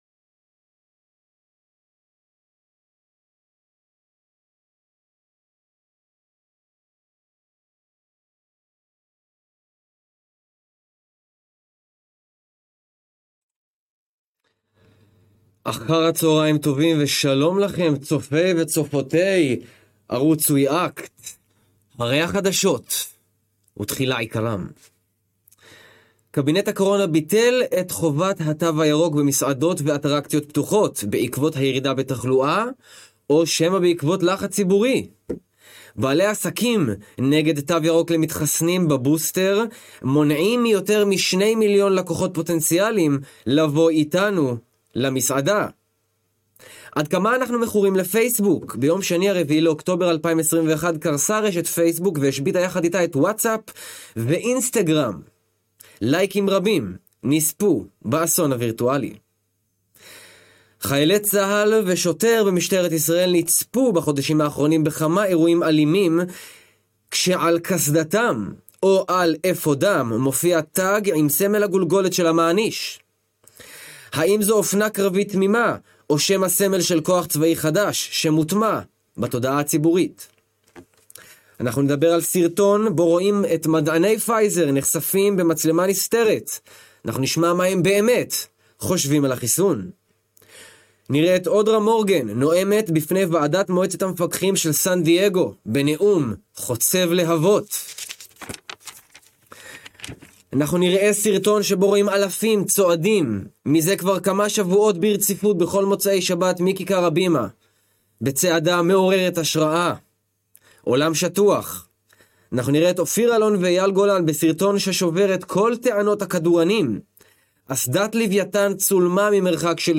הארנב הלבן חוזר בשידור חדשותי היישר מחזית המהפכה לשחרור האנושות.